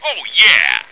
flak_m/sounds/male2/int/M2ohyeah.ogg at 86e4571f7d968cc283817f5db8ed1df173ad3393
M2ohyeah.ogg